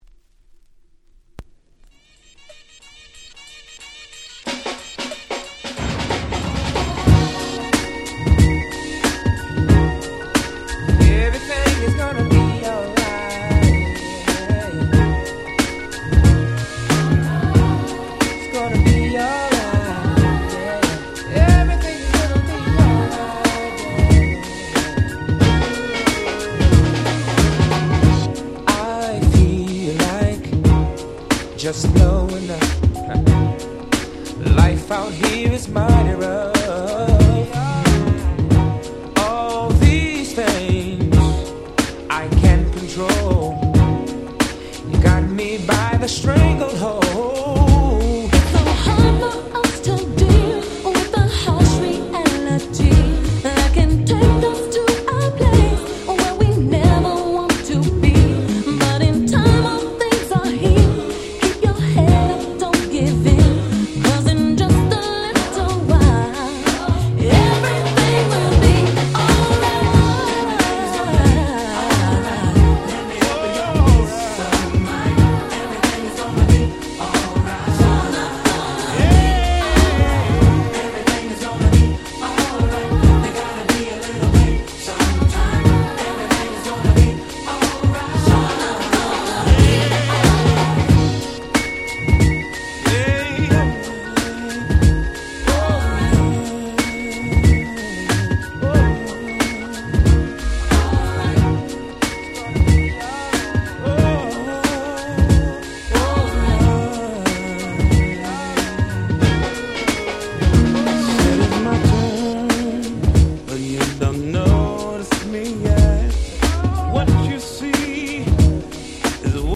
94' Nice R&B EP !!